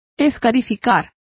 Complete with pronunciation of the translation of scarify.